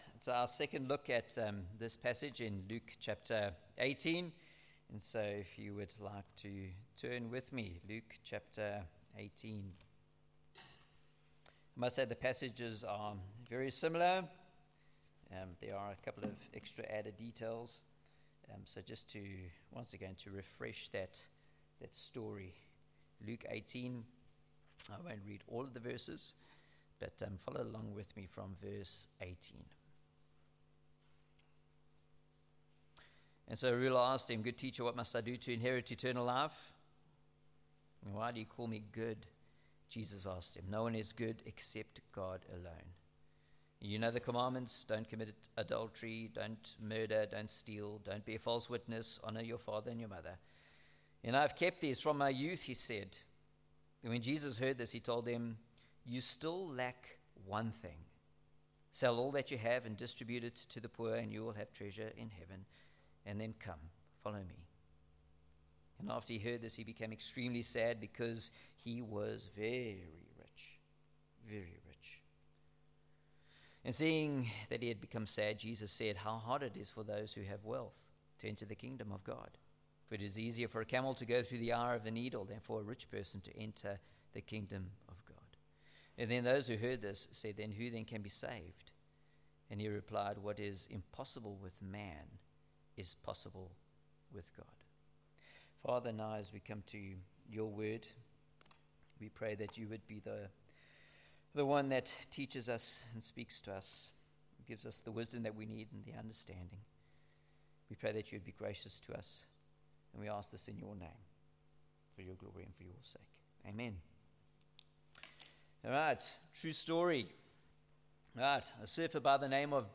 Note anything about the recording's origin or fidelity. Mark 10:17-30 Service Type: Sunday Evening ‘What must I do?’ vs. ‘What have I done?!’